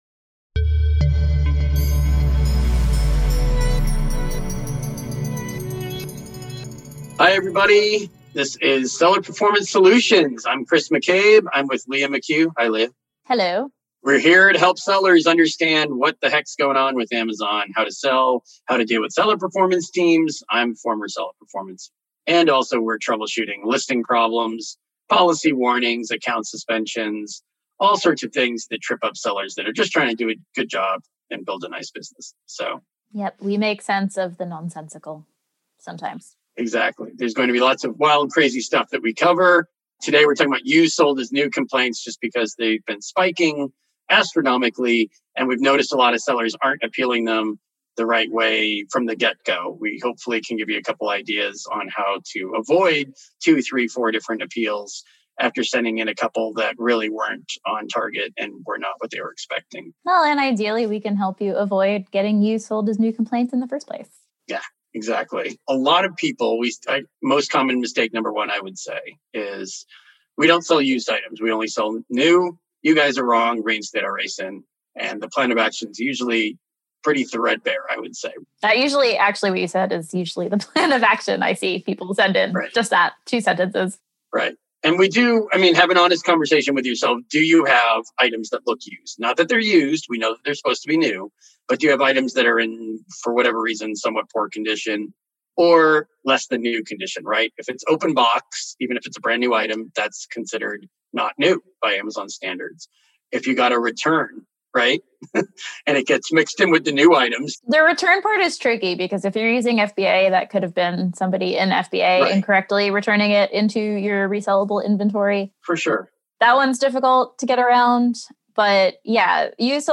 As part of our Scale Your Brand series, we interviewed one of our Seller Velocity sponsors OMG Commerce discussing the best ways to optimize your sales & gain new customers.